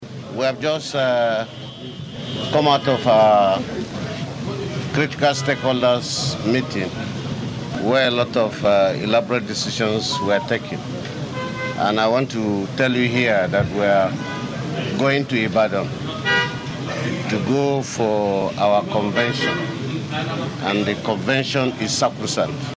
Earlier, PDP governors after a meeting in Bauchi yesterday night, insisted that the party’s 2025 National Elective Convention in Ibadan will be held as scheduled. PDP CONVENTION SOT Adamawa state governor and chairman PDP convention planning committee, Umar Finitiri speaking there.